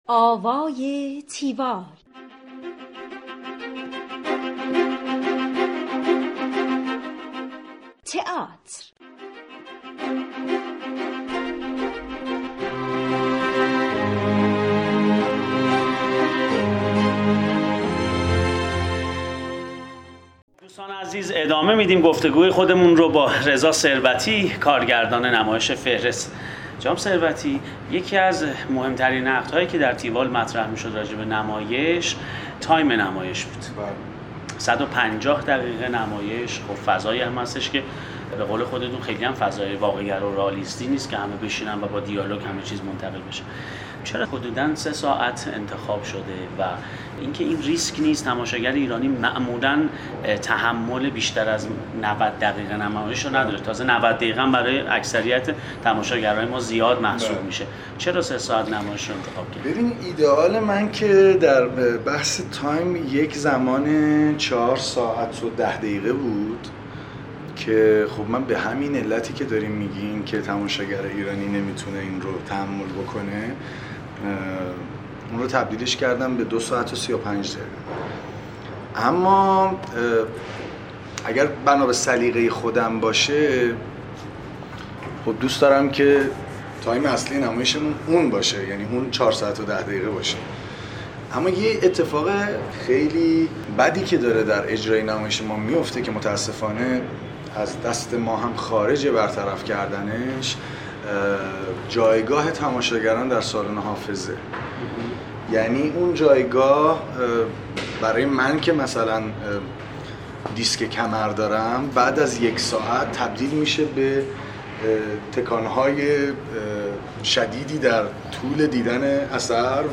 بخش دوم گفتگوی تیوال با